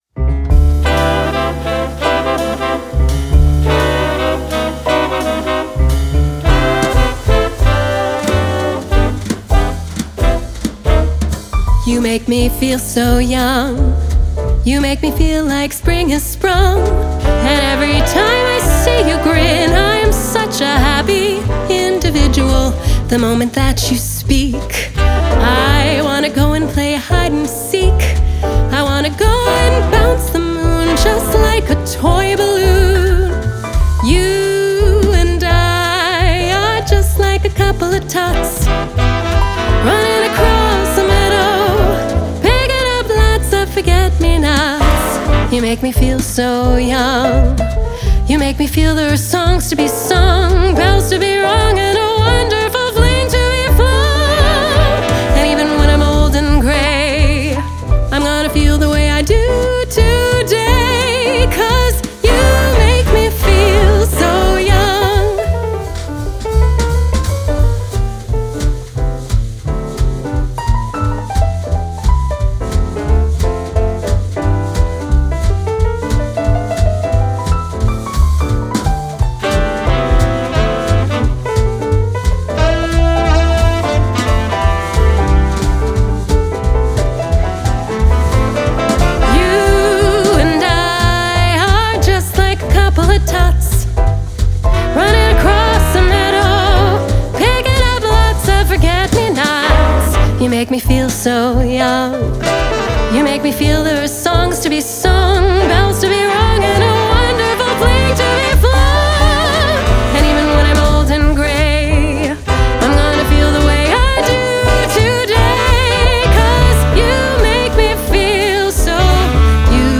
Crossover soprano